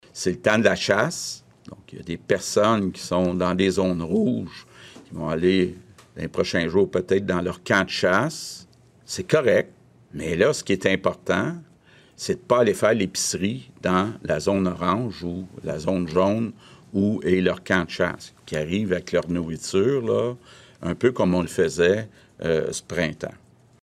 François Legault a aussi apporté des précisions pour les chasseurs qui demeurent dans  des zones rouges.  Ces derniers pourront aller chasser dans des zones oranges ou jaunes mais en respectant certaines mesures :